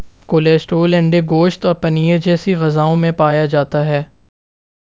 deepfake_detection_dataset_urdu / Spoofed_TTS /Speaker_08 /10.wav